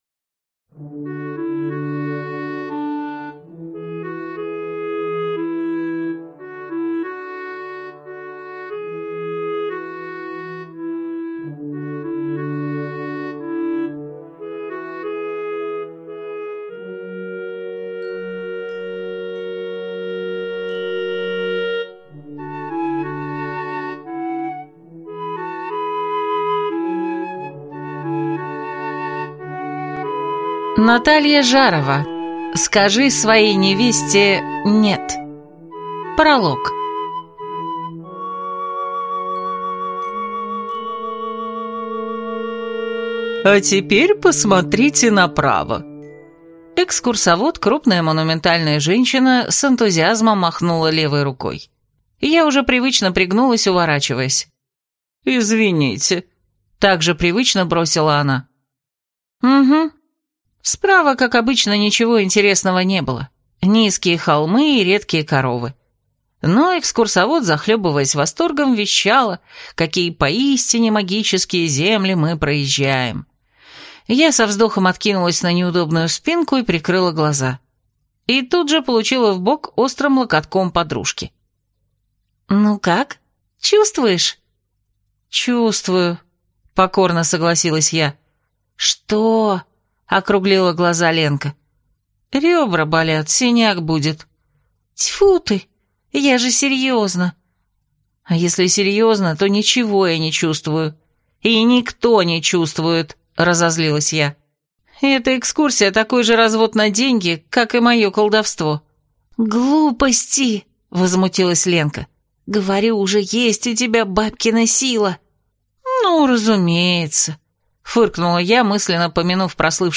Аудиокнига Скажи своей невесте «Нет» | Библиотека аудиокниг